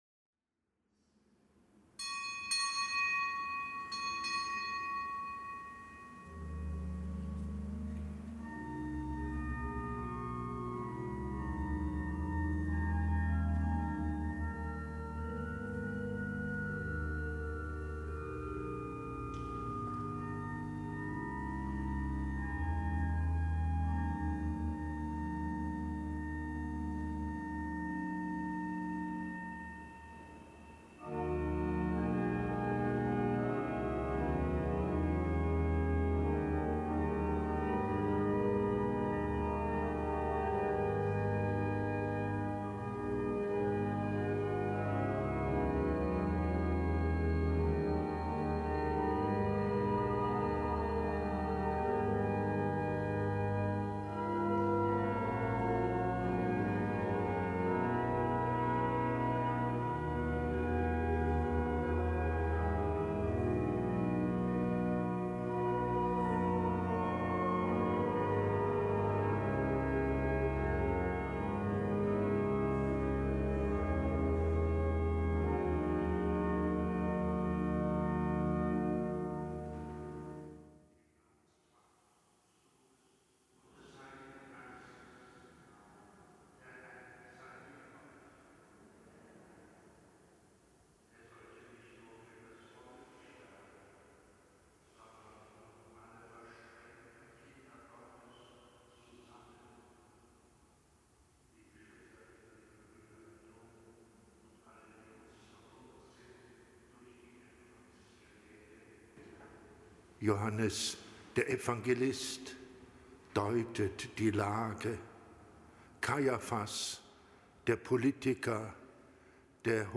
Kapitelsmesse am Samstag der 5. Fastenwoche